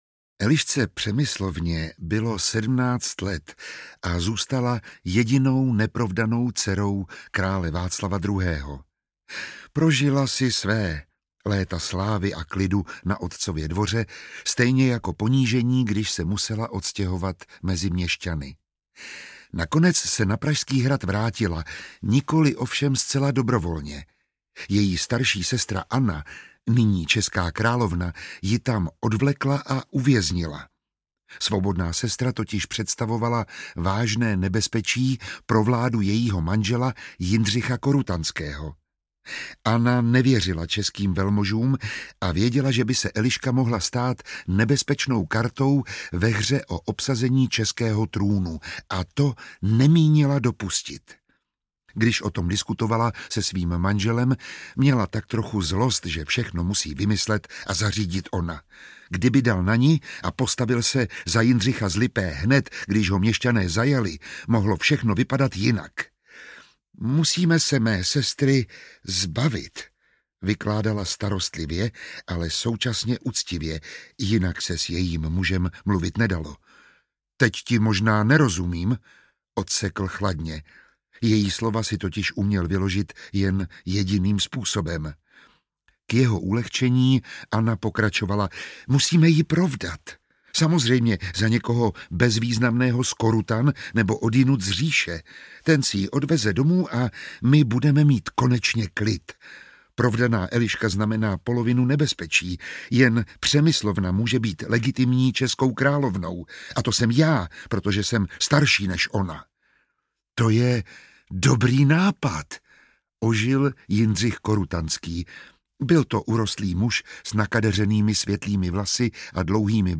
Lucemburská epopej I - Král cizinec (1309 – 1333) audiokniha
Ukázka z knihy
Čte Miroslav Táborský.
Vyrobilo studio Soundguru.